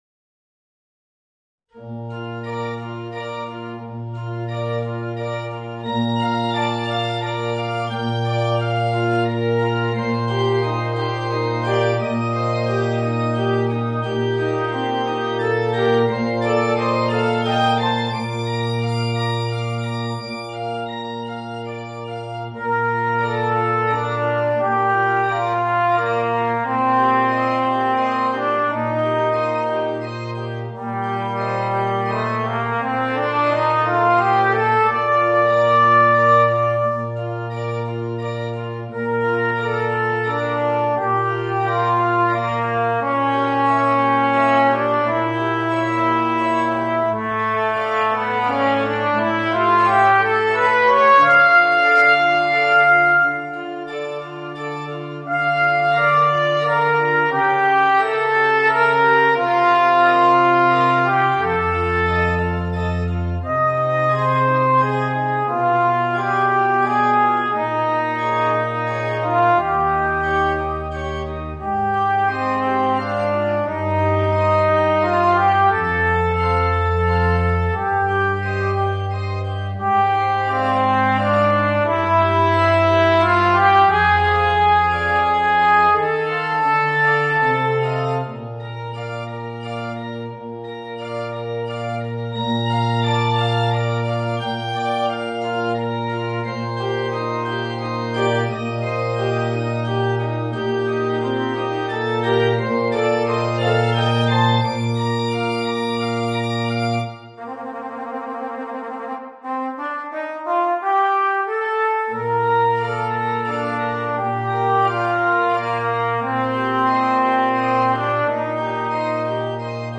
Voicing: Trumpet and Organ